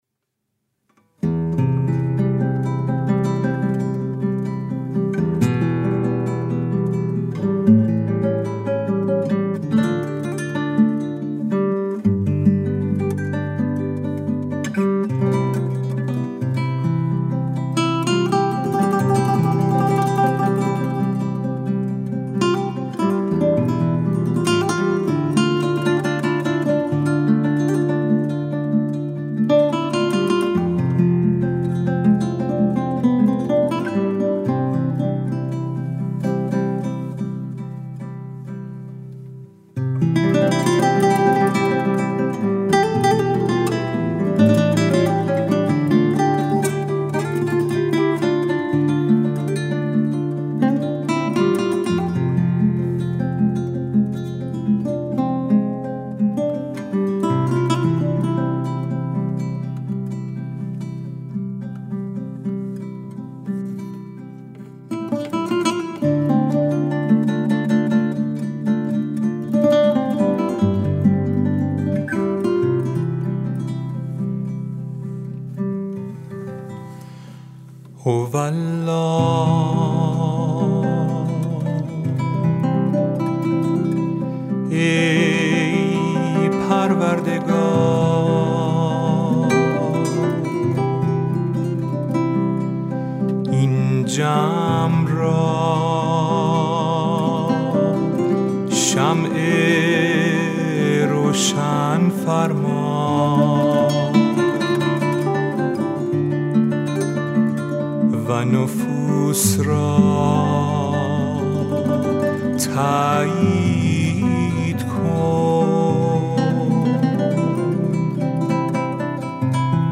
مجموعه مناجات های فارسی همراه با موسیقی